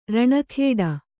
♪ raṇa khēḍa